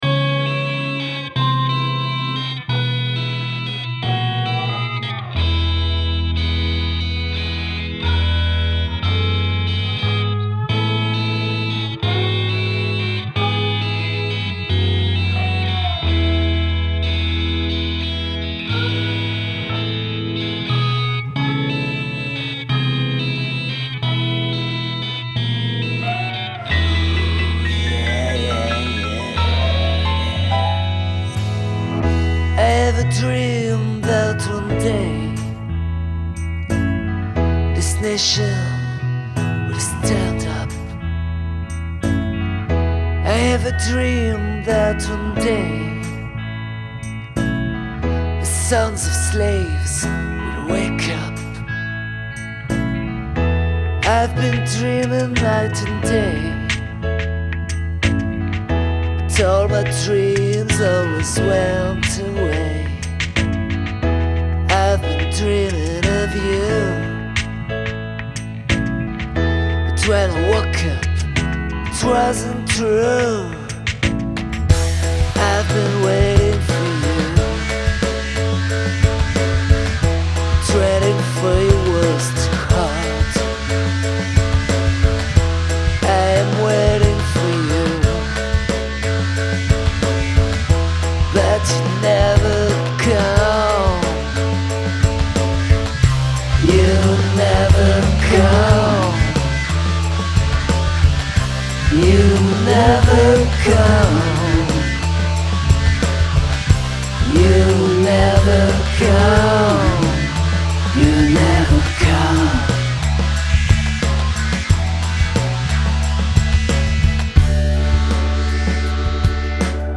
voix, guitare, clavier, basse, programmation
voix, guitare, clavier, programmation, mixage